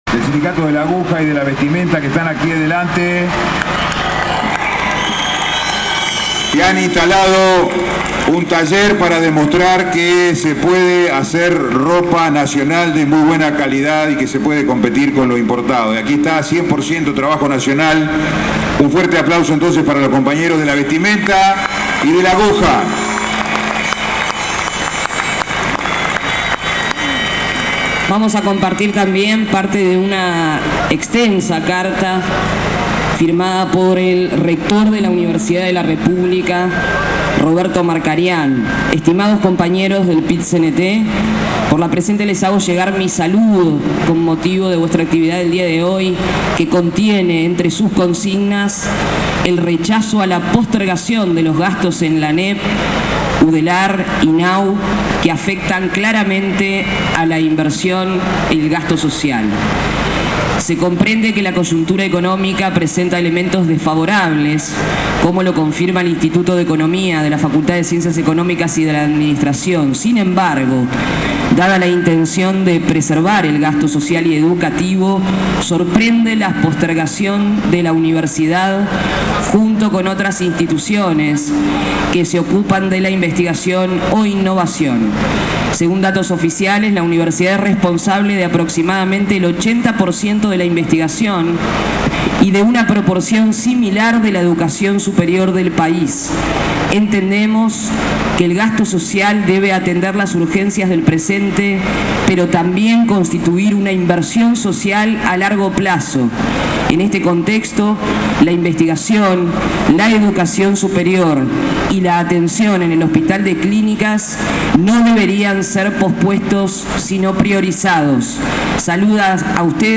Oratoria e imágenes del Paro Parcial del 29 /06 – Portal SUTEL – Sindicato Único de Telecomunicaciones
El secretario general del PIT-CNT, Marcelo Abdala, sostuvo que no se contuvo a la inflación como preveía el Ejecutivo y, ante esto, deben de reverse las pautas salariales.